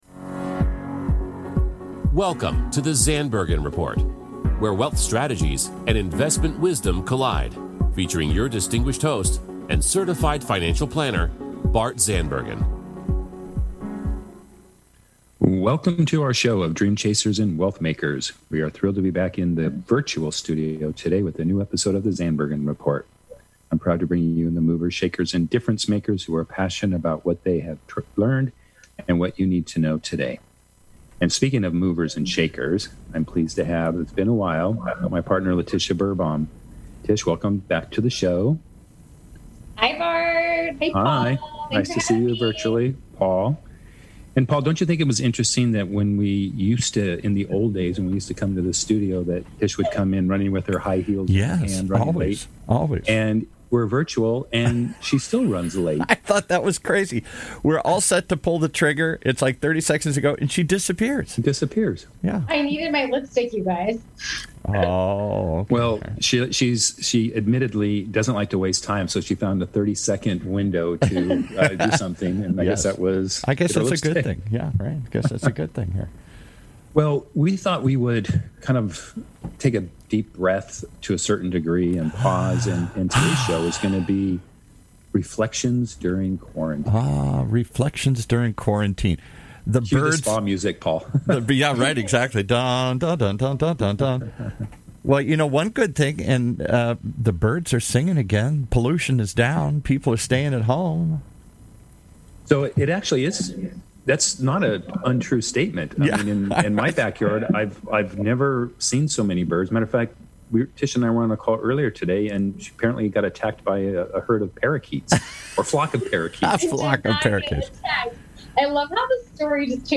in the virtual studio
candid conversation about life in quarantine.